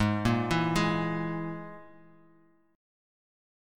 Absus2#5 Chord